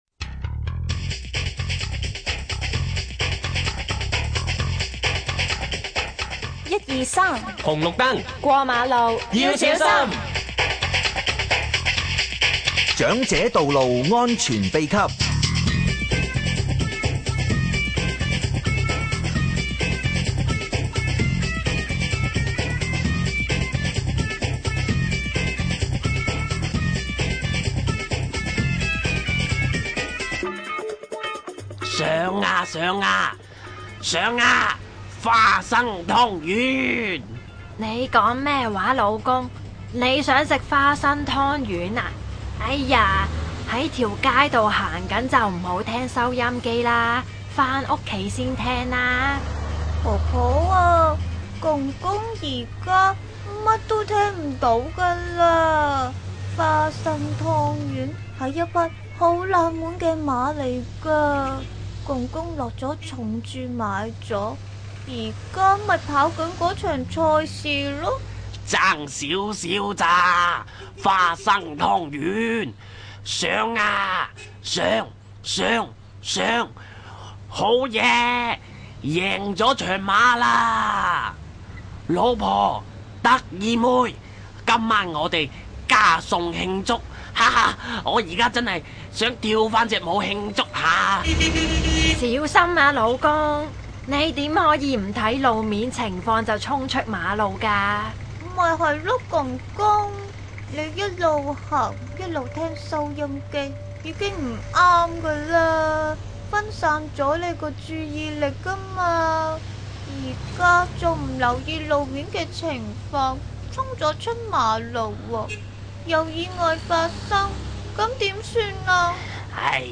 公公醒哥、婆婆醒嫂和孫女得意妹會在十集廣播劇《長者道路安全秘笈》，傳授你道路安全小貼士。